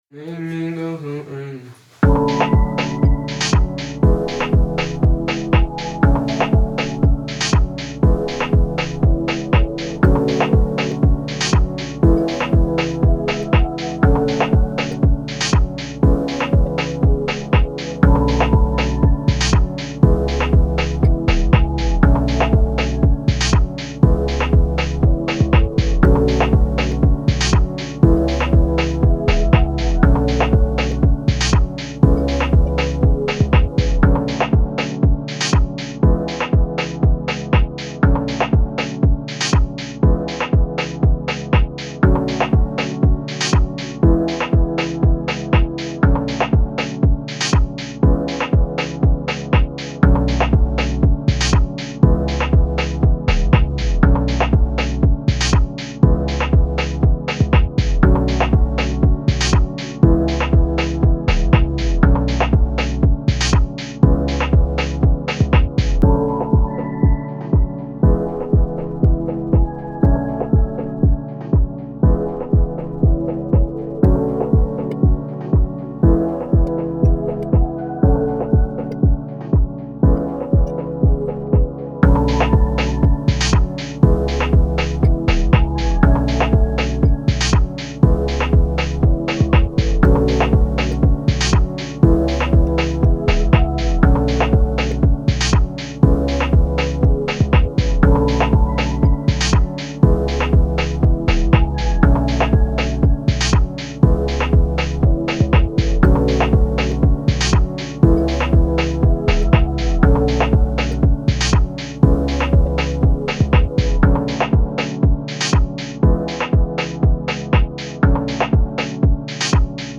Afrobeat, Pop
F# Minor